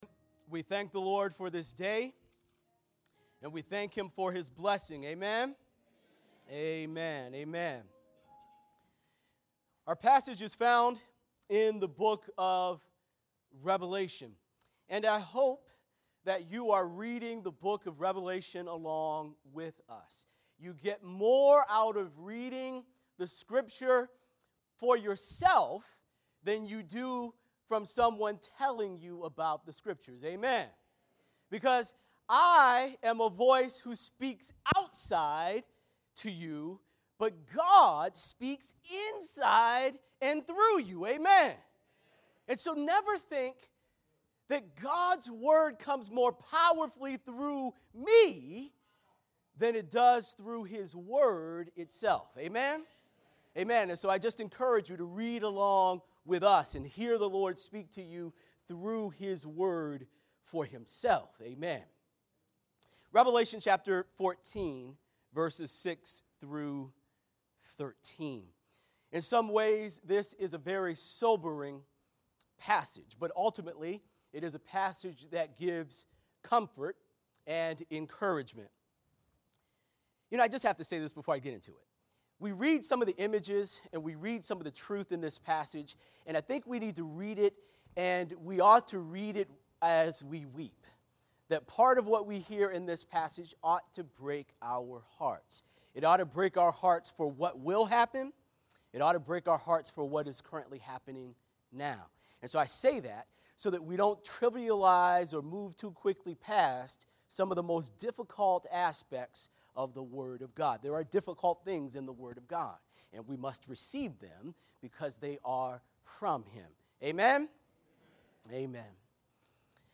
Crossroads Church of Hillside Sermons